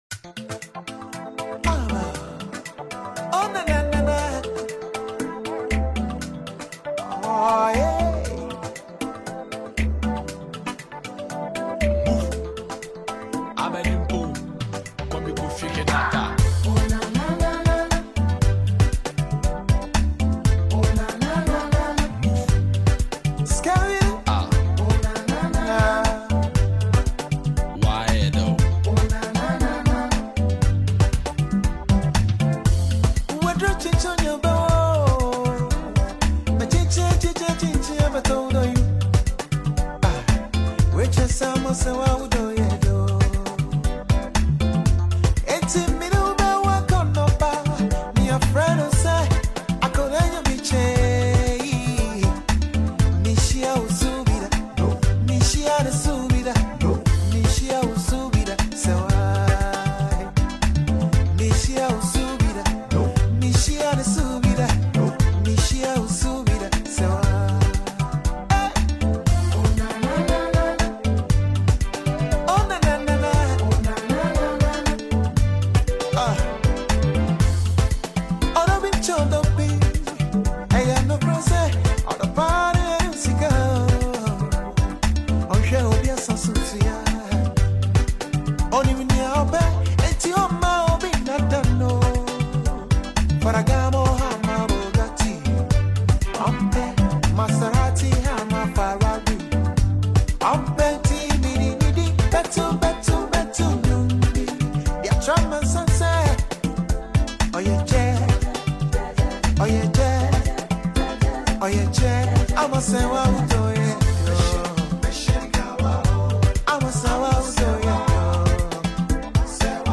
Ghanaian highlife